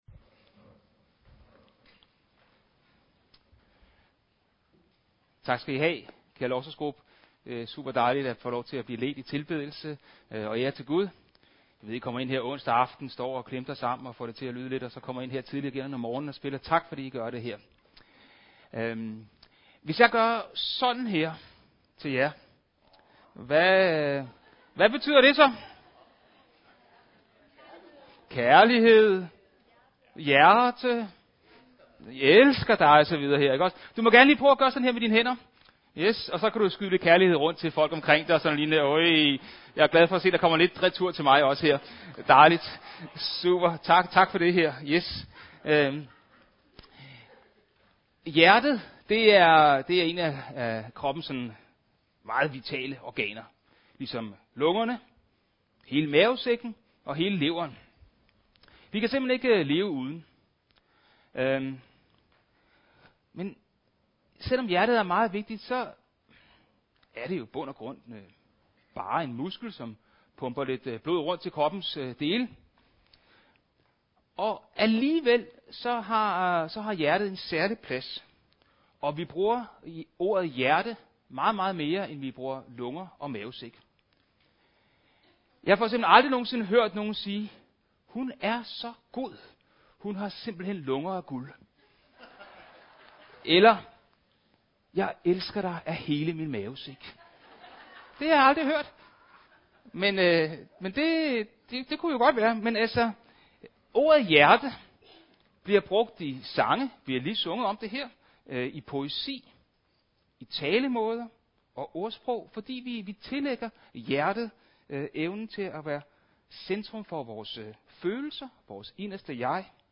25. juni 2023 Type af tale Prædiken MP3 Hent til egen PC